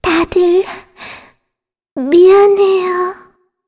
Shea-Vox_Dead_kr.wav